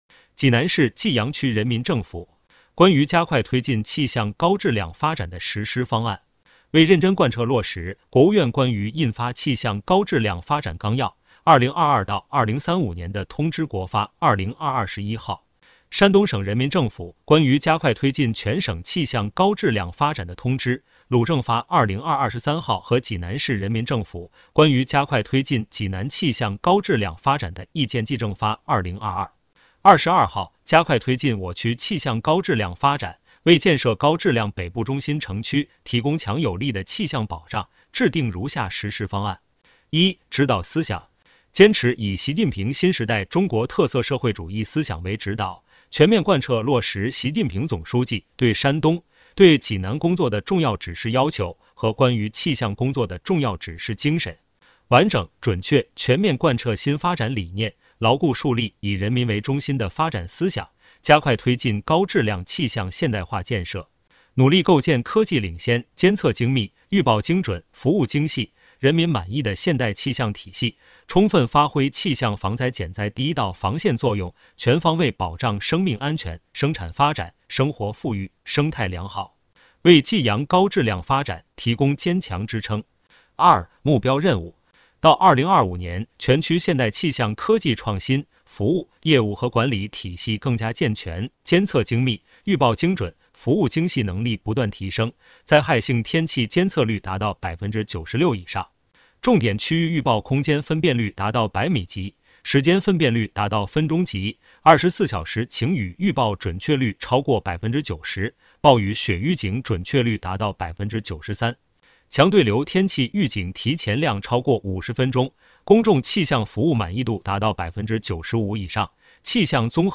【有声朗读】《加快推进气象高质量发展的实施方案》